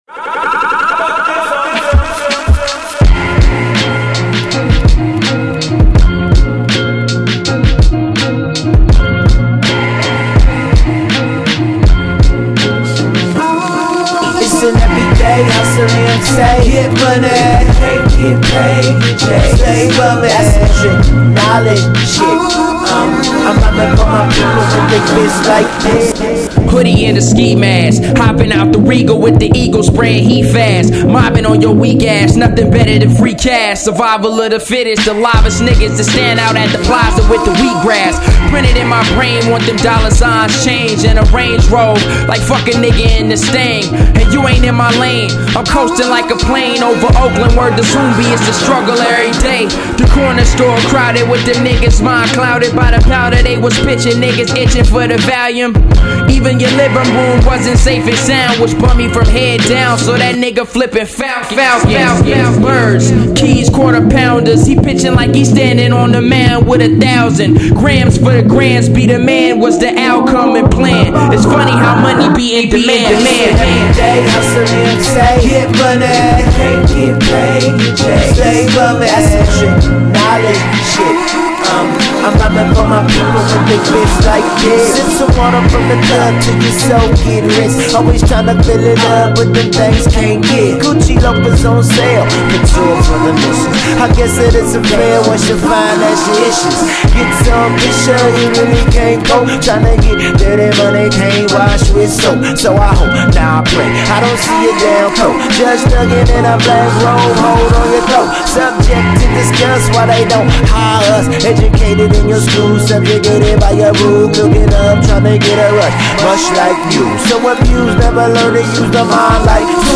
dope young emcee